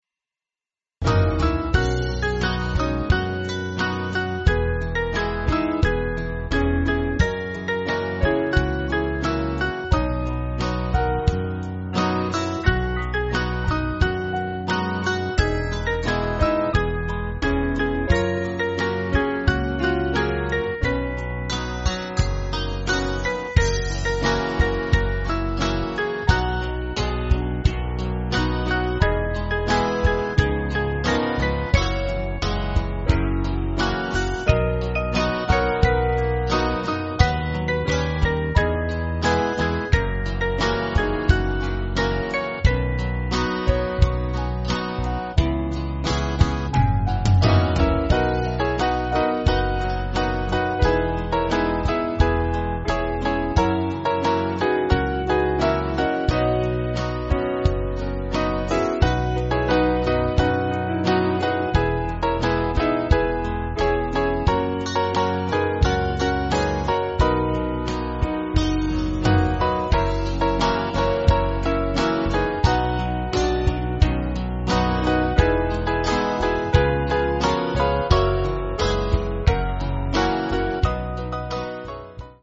Country/Gospel